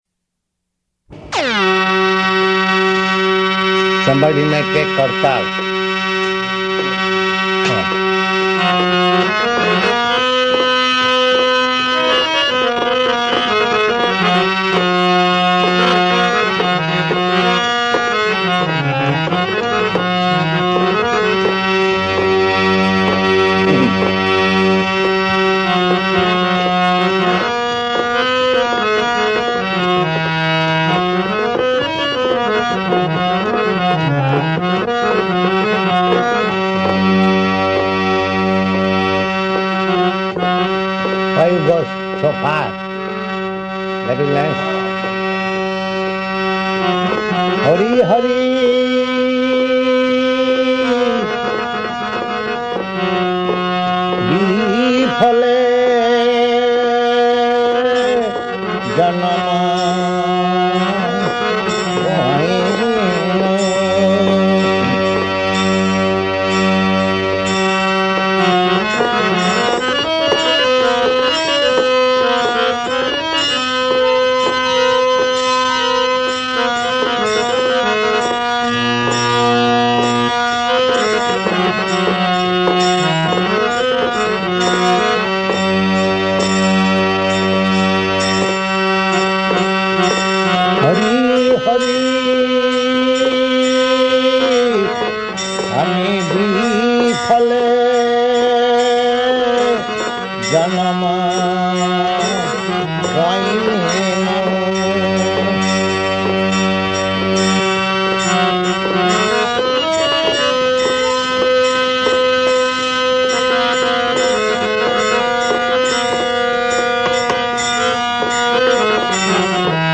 Srila Prabhupada sings and explains Hari Hari Biphale by Srila Narottama dasa Thakura
Prabhupāda: (sings) (playing harmonium)